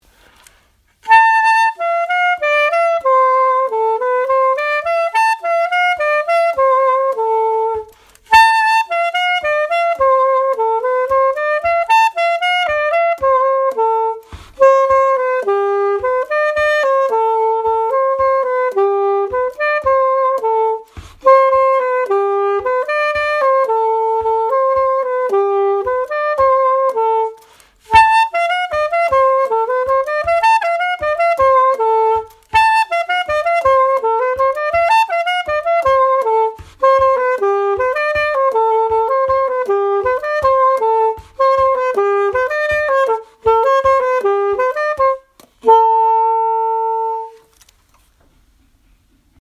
Svenska Folkdansar Häfte 4 nr 4 - Klarinett!
svenskafolkdansarhafte4nr4-klarinett.mp3